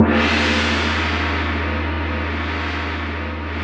Index of /90_sSampleCDs/Roland - Rhythm Section/PRC_Asian 2/PRC_Gongs
PRC R8 GONG.wav